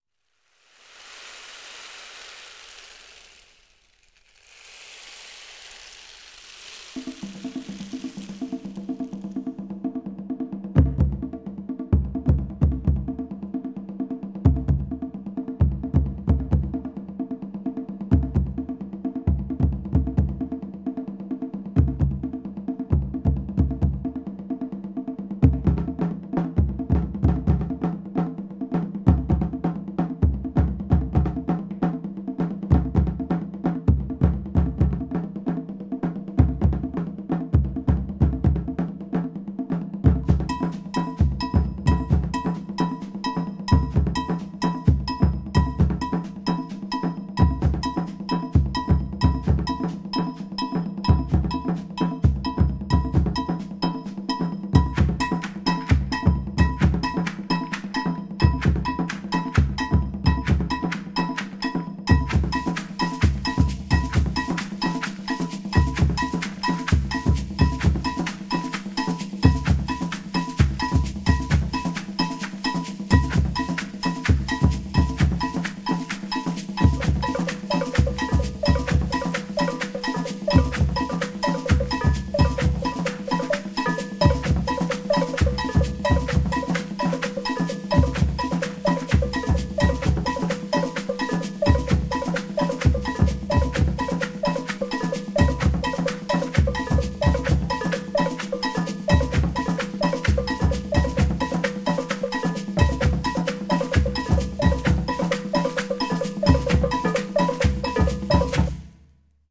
Afrikos musamieji.mp3